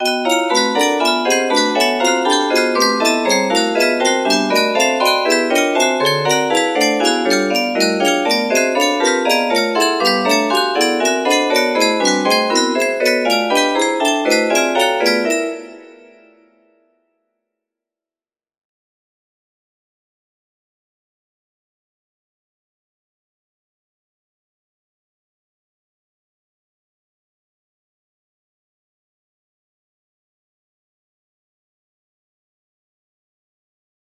P5 music box melody